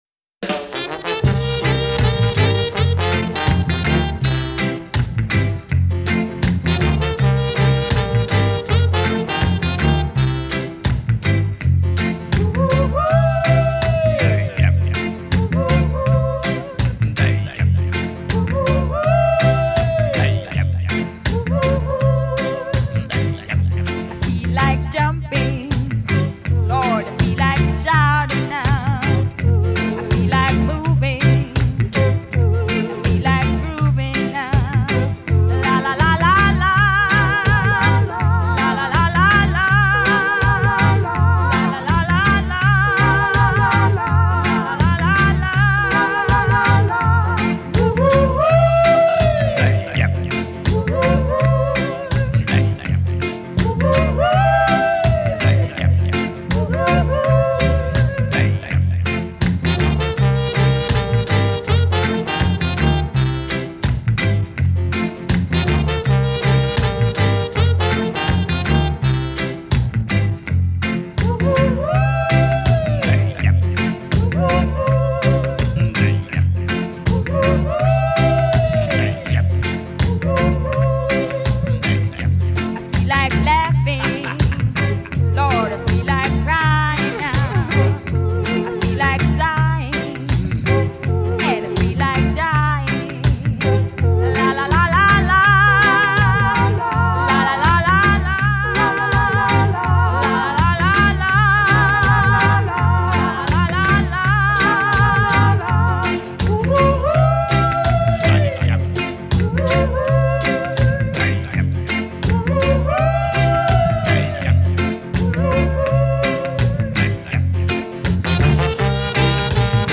a perfect slab of baked in the sun pop-reggae